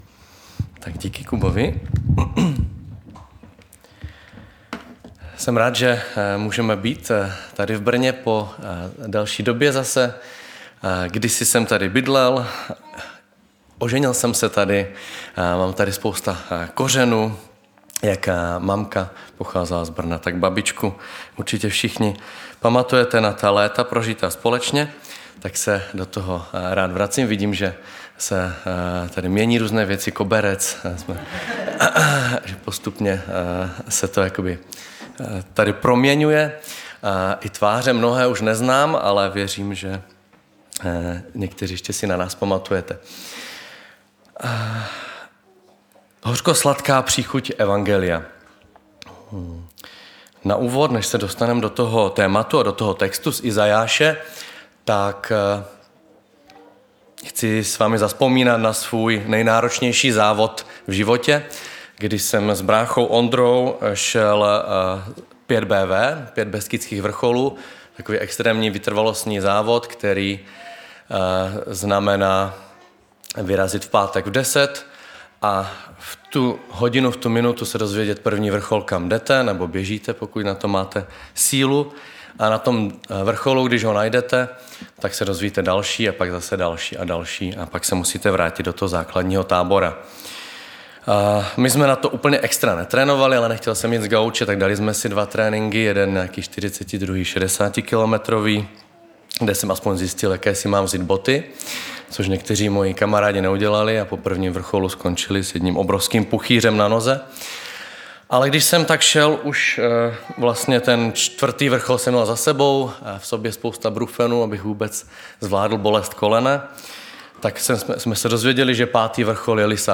Nedělní vyučování